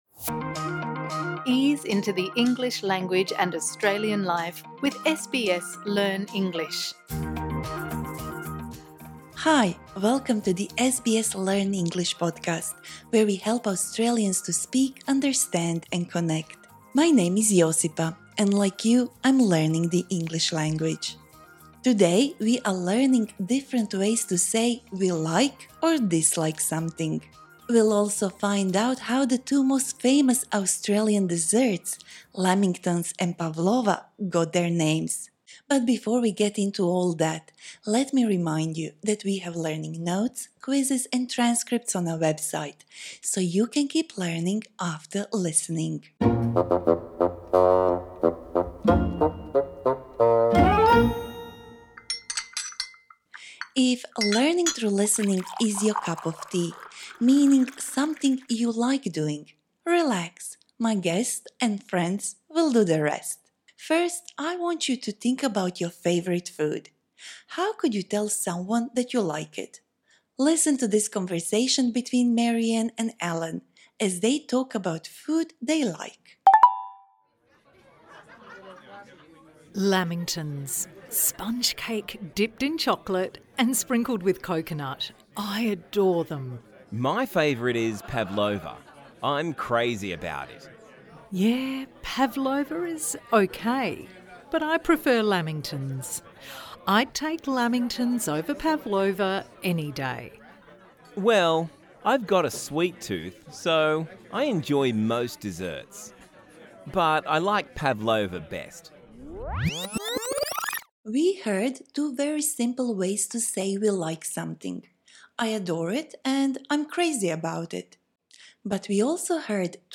This lesson suits upper-intermediate to advanced learners.